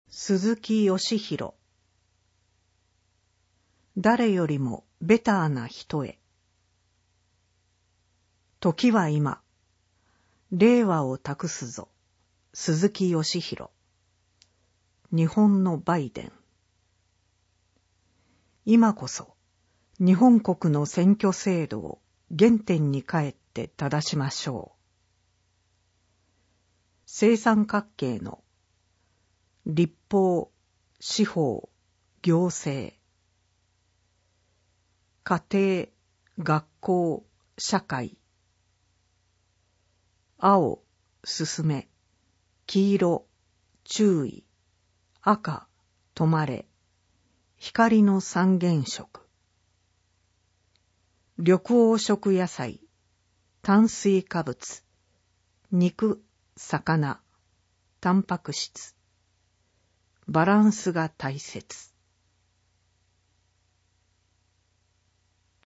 選挙のお知らせ版（音声版）